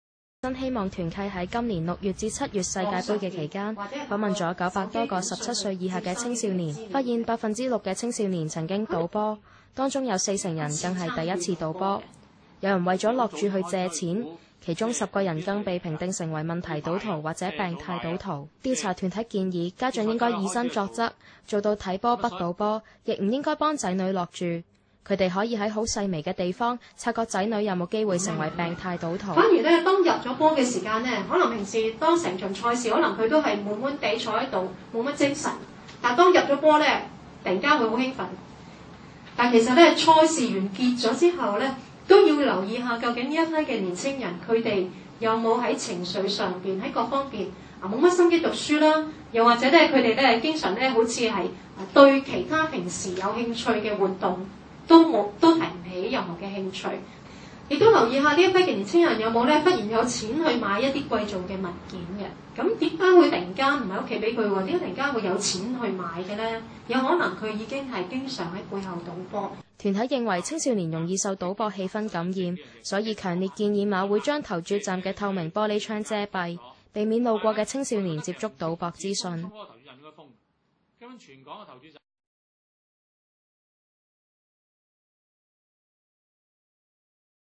調查：世界盃期間6%青少年曾賭波（香港電台新聞報導 ）